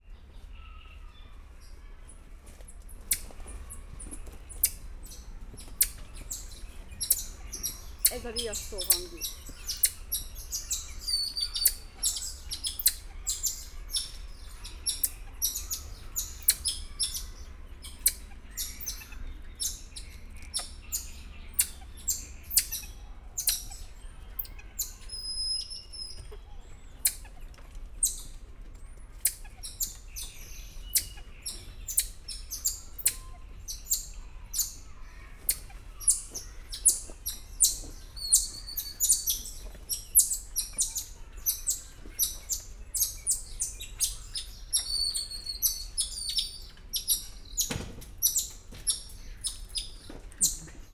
Directory Listing of //allathangok/szegedizoo2011_standardt/feherfejuselyemmajom/
riasztojelzes00.50.WAV